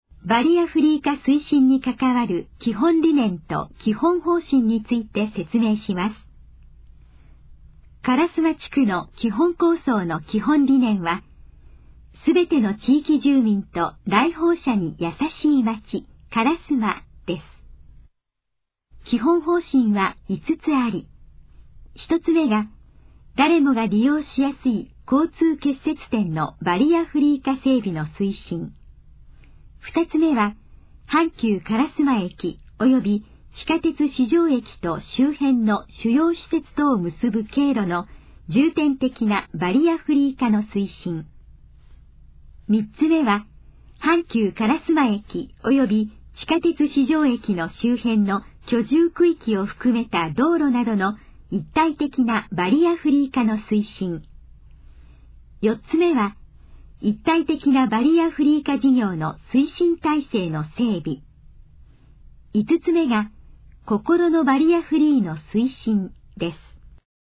このページの要約を音声で読み上げます。
ナレーション再生 約135KB